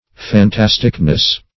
Fantasticness \Fan*tas"tic*ness\, n.